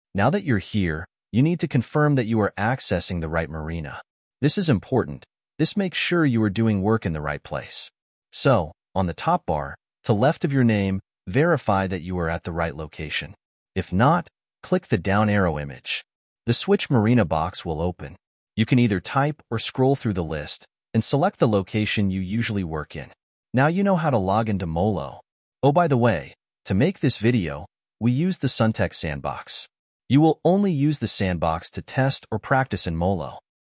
Text-to-Speech Audio for Narration
We use AI-generated text-to-speech audio to narrate digital learning.